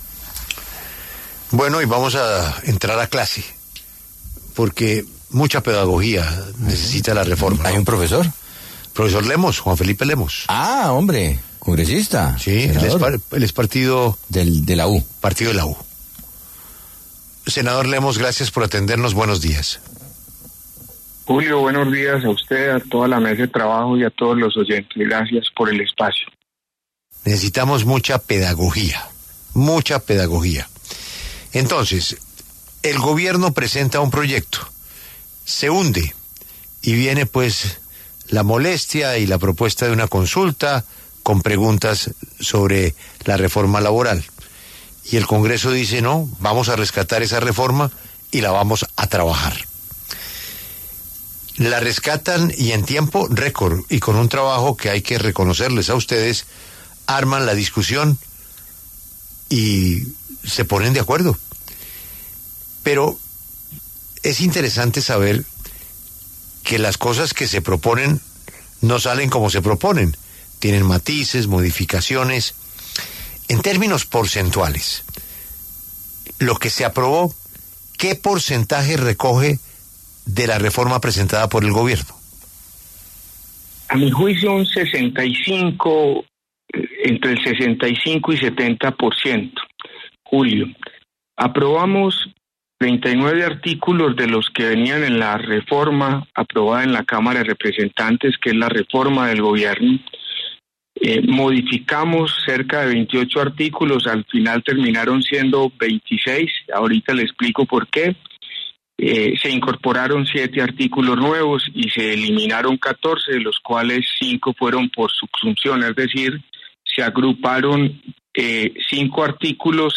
El senador Juan Felipe Lemos, ponente del proyecto y del Partido de la U, pasó por los micrófonos de La W.